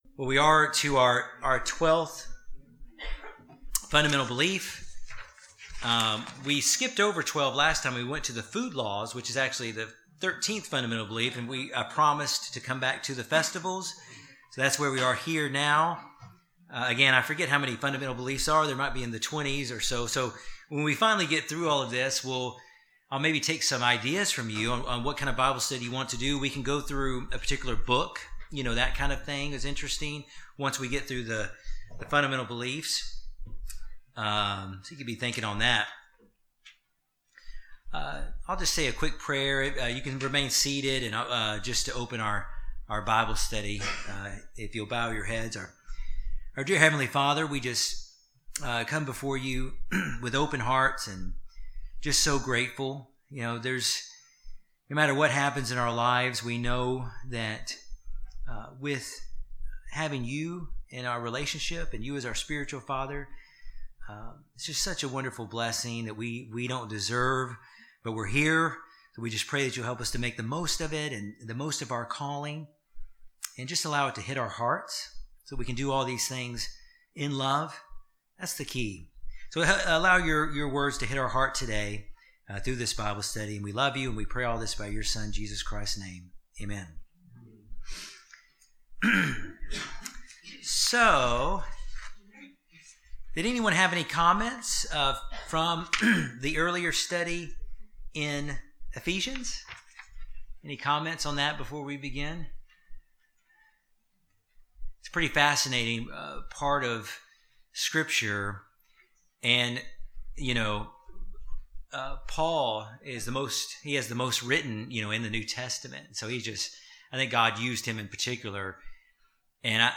Bible Study: God's Holy Days and the importance and parallel they represent in the Christian's walk with Jesus Christ.